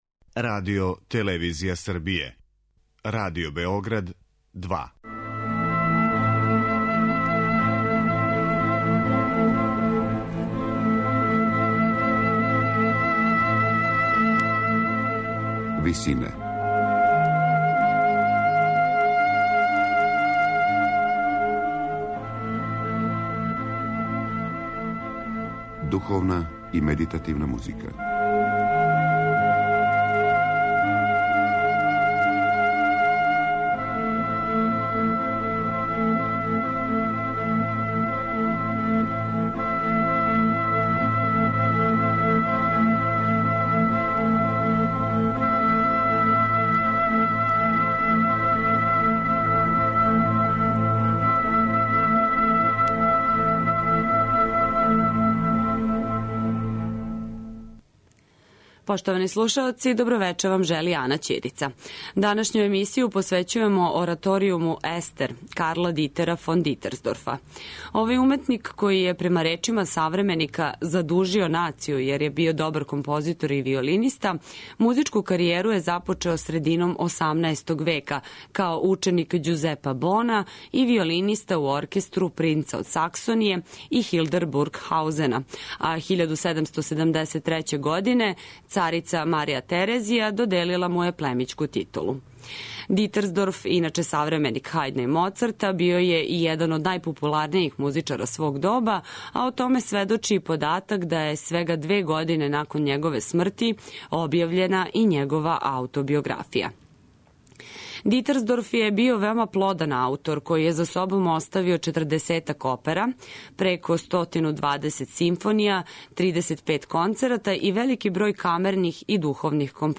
Данашњу емисију посвећујемо ораторијуму 'Естер' Карла Дитера фон Дитерсдорфа.
медитативне и духовне композиције